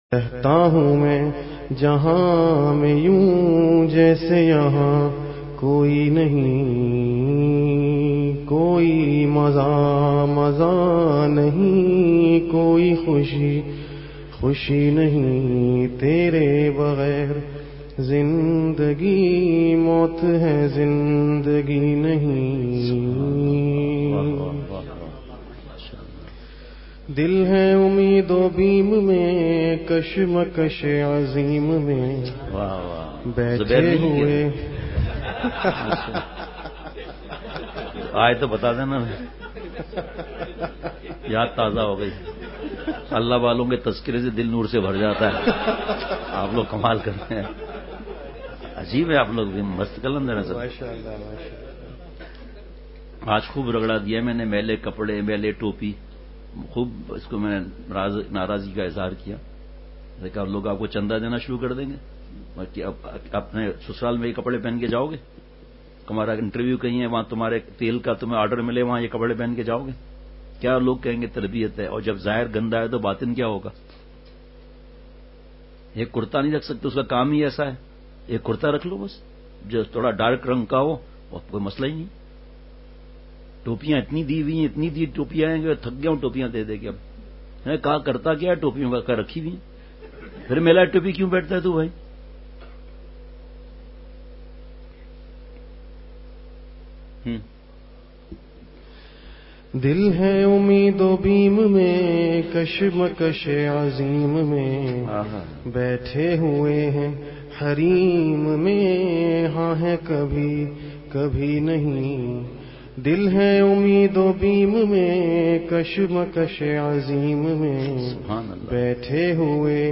اصلاحی مجلس کی جھلکیاں
خوب جوش سے ایسا بیانِ درد دل فرمایا کہ کلیجے منہ کو آگئے